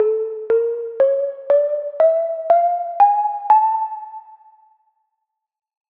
Double Harmonic
2025-kpop-scale-DH.mp3